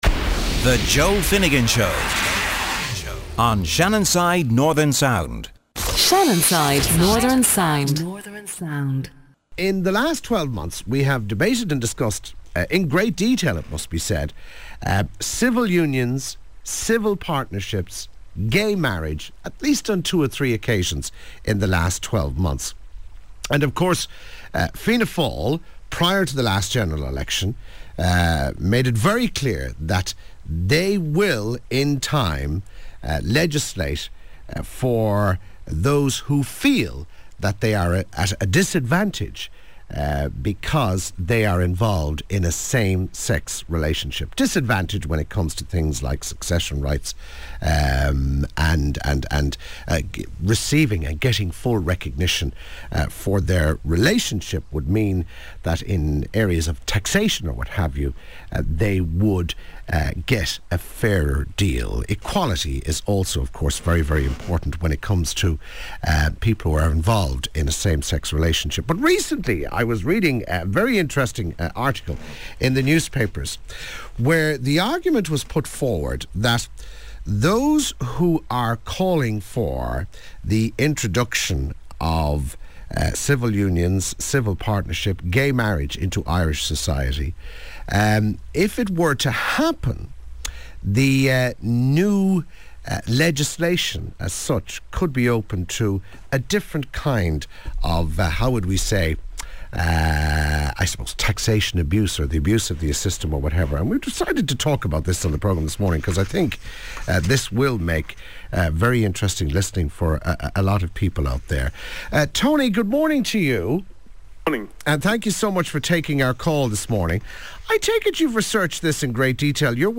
Radio Debate On 17th January, I was invited to debate the gay marriage issue with a very polite Councillor from the ruling Fianna Fail party, called Malcolm Byrne , who is openly gay . Shannonside FM were the host